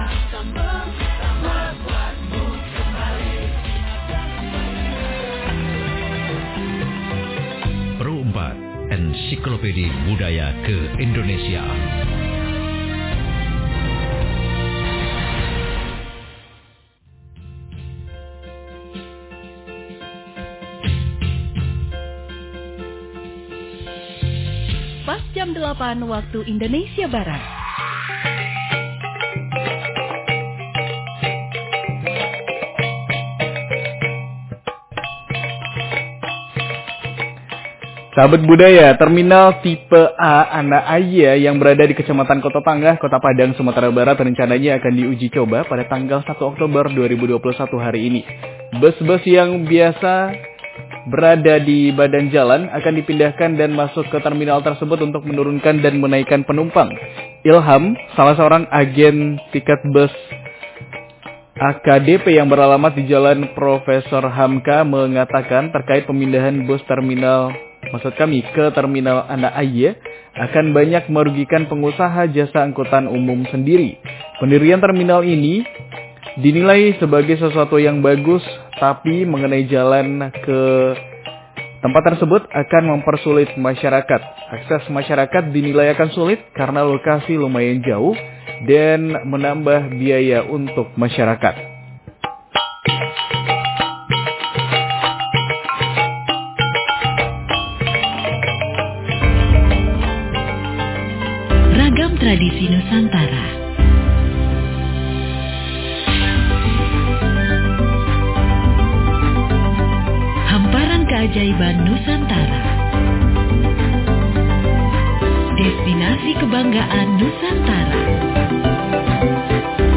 Dialog Interaktif Jelajah Nagari Jum'at Tanggal 01 Oktober 2021 .Pro 4 fm 92,4 Mhz.